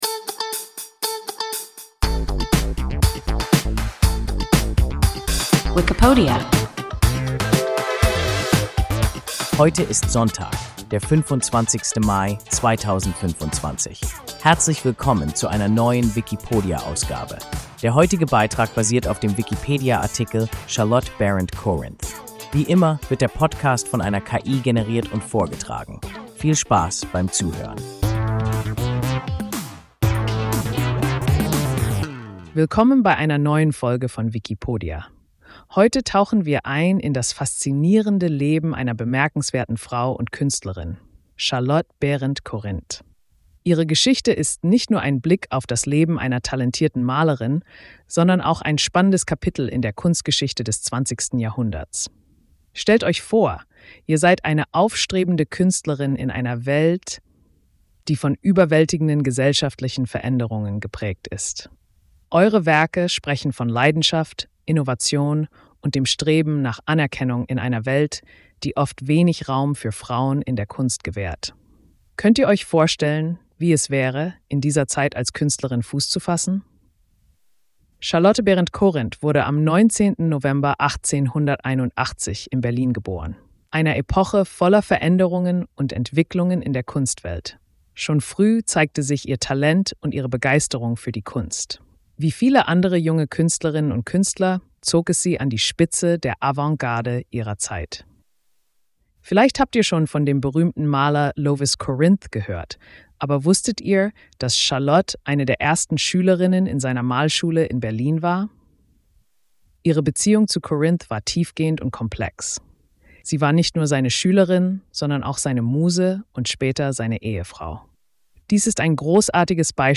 Charlotte Berend-Corinth – WIKIPODIA – ein KI Podcast